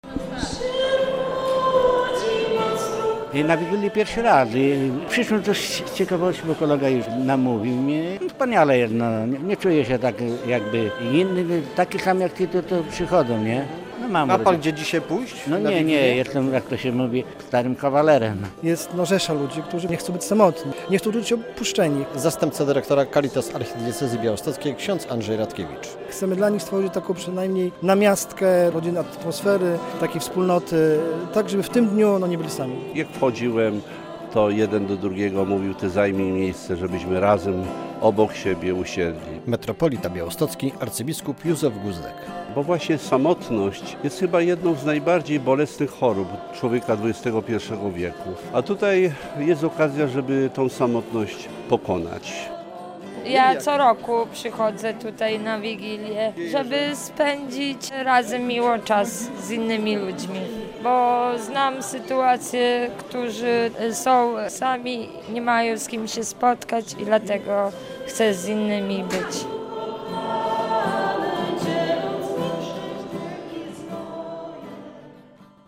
Wigilia dla potrzebujących i samotnych w Białymstoku - relacja